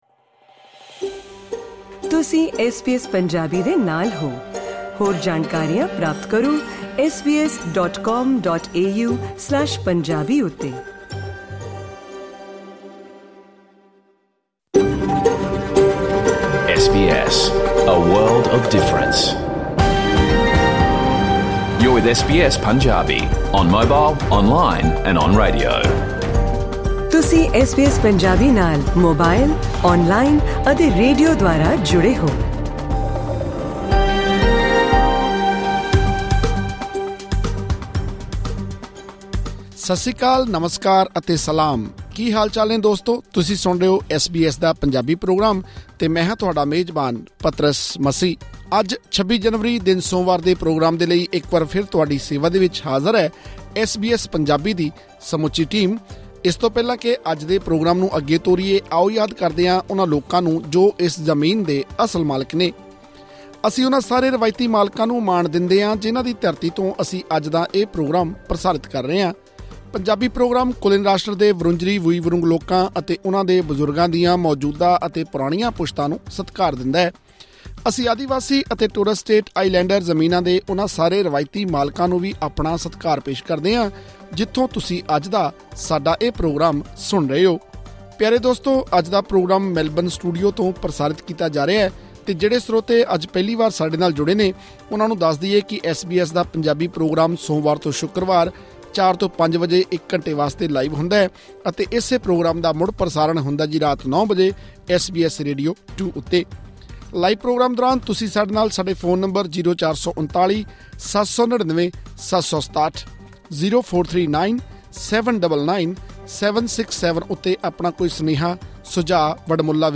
ਐਸ ਬੀ ਐਸ ਪੰਜਾਬੀ ਦਾ ਰੇਡੀਓ ਪ੍ਰੋਗਰਾਮ ਸੋਮਵਾਰ ਤੋਂ ਸ਼ੁੱਕਰਵਾਰ ਸ਼ਾਮ 4 ਵਜੇ ਤੋਂ 5 ਵਜੇ ਤੱਕ ਲਾਈਵ ਪ੍ਰਸਾਰਿਤ ਹੁੰਦਾ ਹੈ।